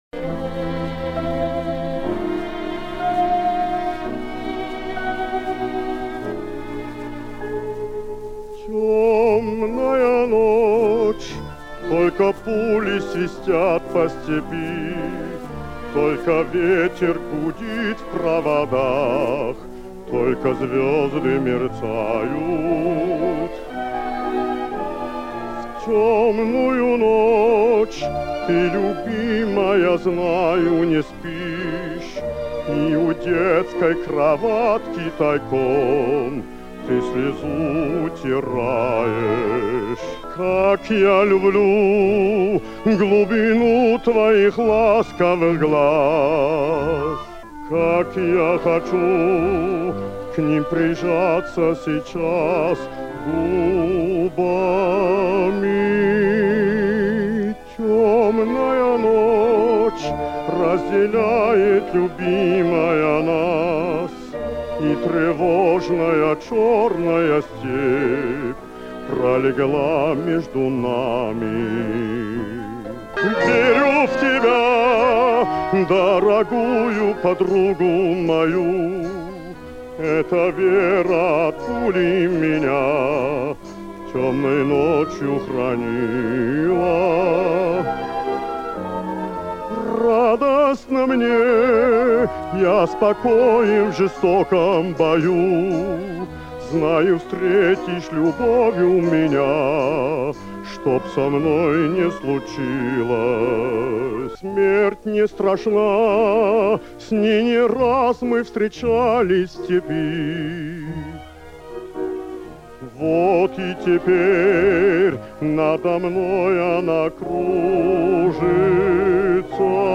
прекрасного баса